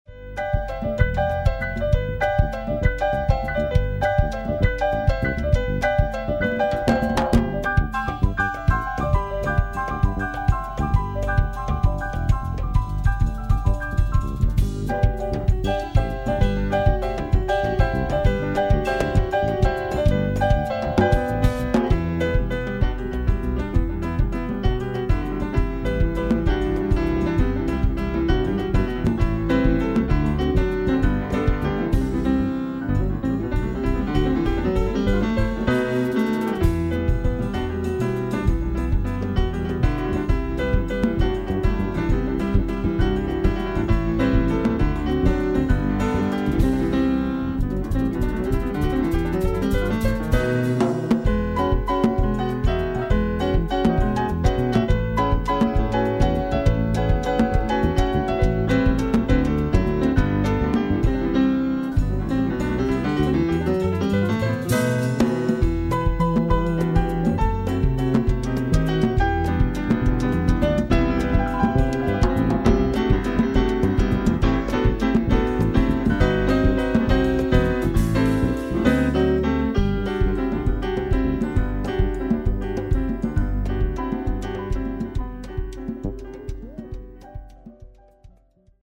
MUSIC FROM LIVE JAZZ FESTIVAL 2002: